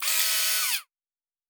Sci-Fi Sounds / Mechanical / Servo Small 8_3.wav
Servo Small 8_3.wav